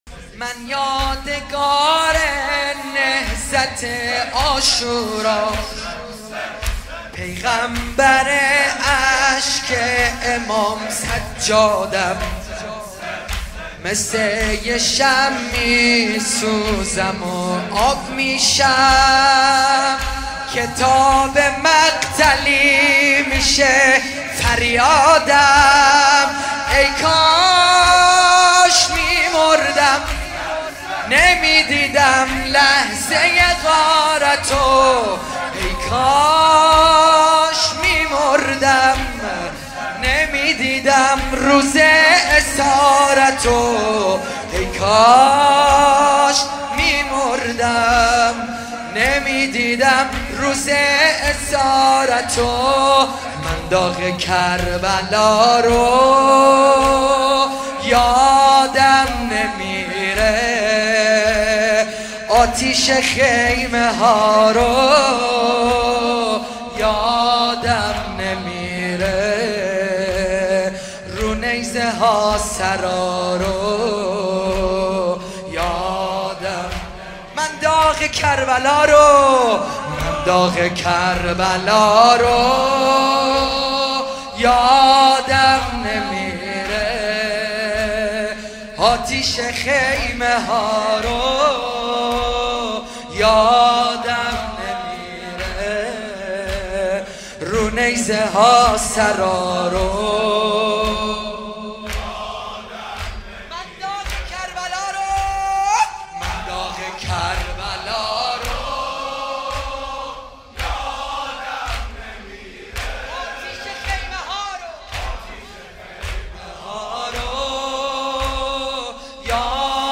زمینه | من یادگار نهضت عاشورام، پیغمبر اشک امام سجادم
مداحی
مراسم عزاداری شهادت امام محمد باقر(ع)